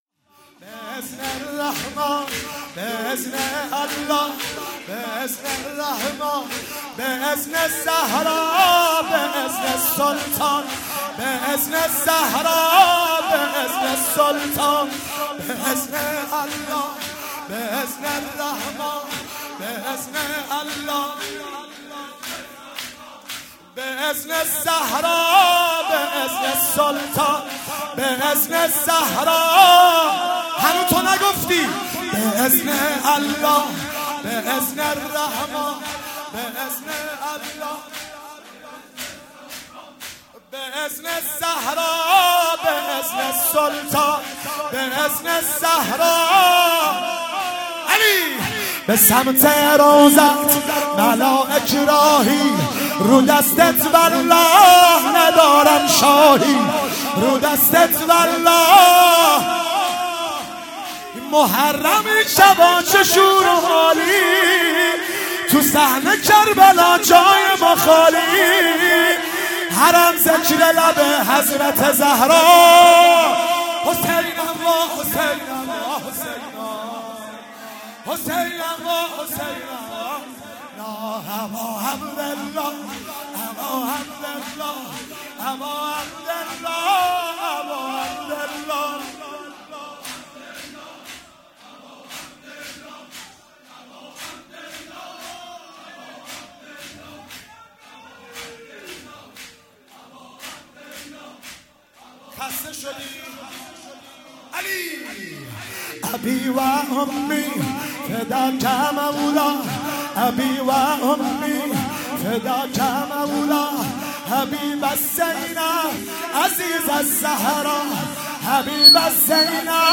سه ضرب